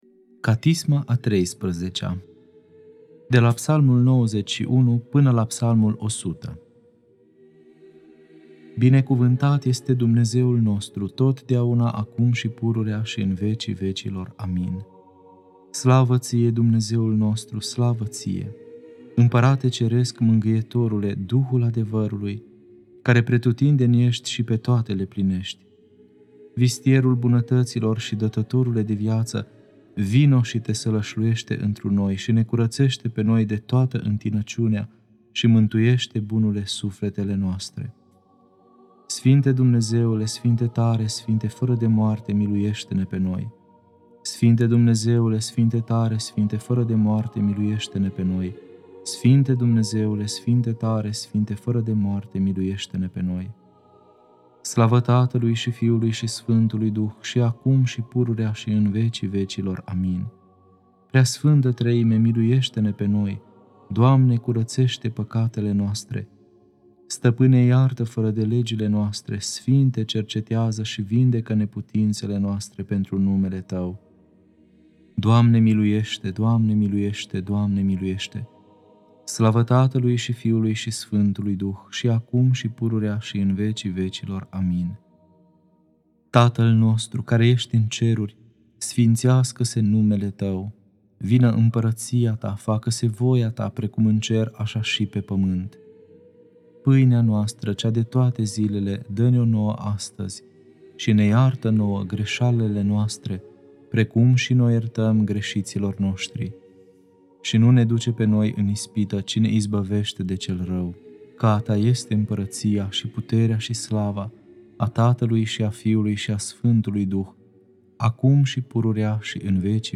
Catisma a XIII-a (Psalmii 91-100) Lectura